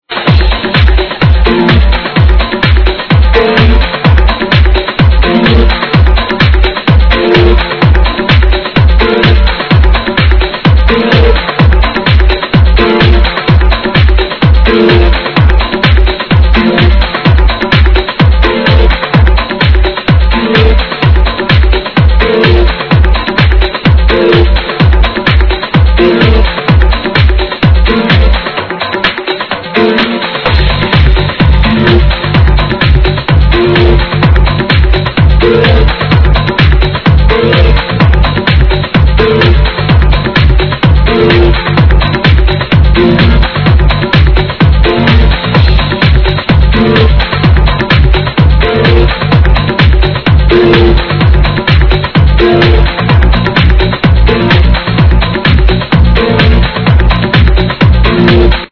Please ID this Tune from 05...House